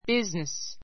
business A1 bíznis ビ ズ ネ ス 名詞 ❶ 仕事 , 任務; 職業; 用事 a business trip a business trip 出張 His father is a carpenter, and his business is building houses.